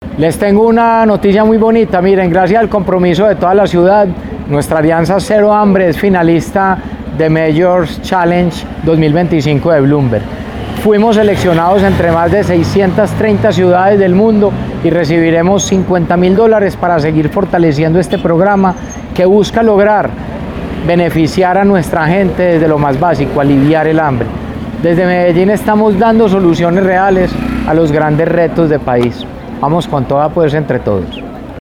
Declaraciones alcalde de Medellín, Federico Gutiérrez Zuluaga.
Declaraciones-alcalde-de-Medellin-Federico-Gutierrez-Zuluaga.-Global-Mayor.mp3